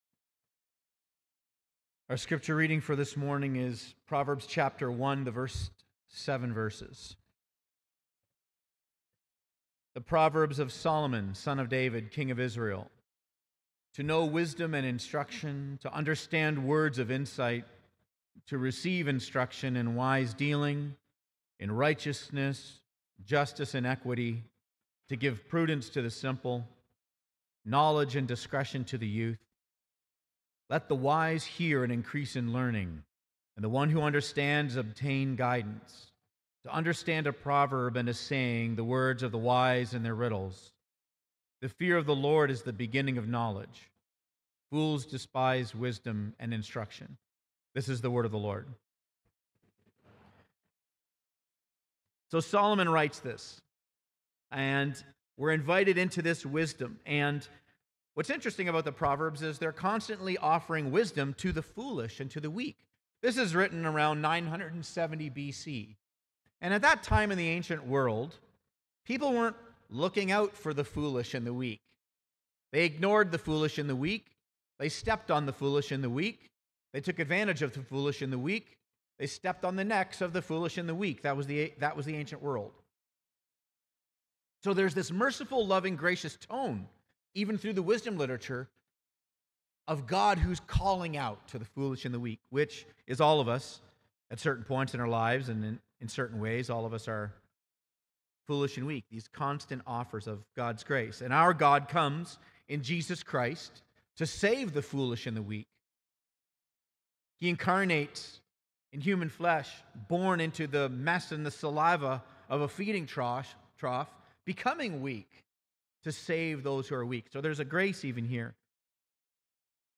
sermon-aug-3-2025.mp3